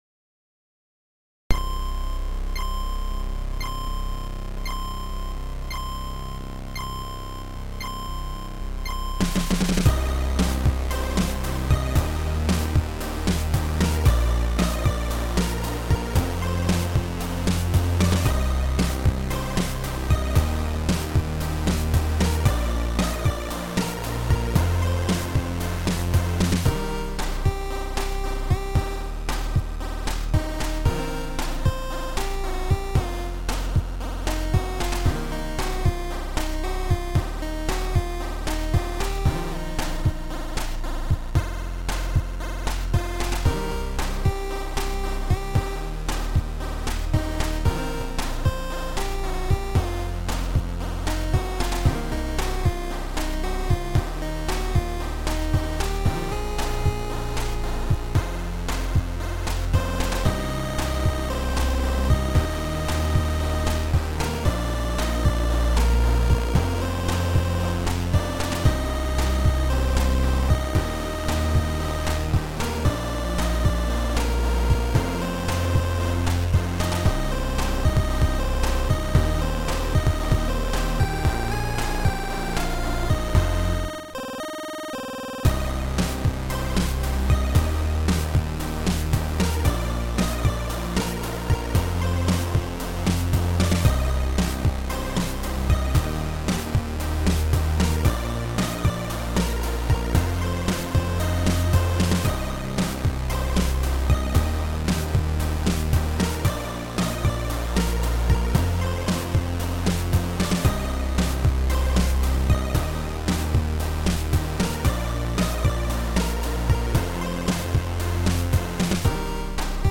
Fresh cover of a still pretty fresh song.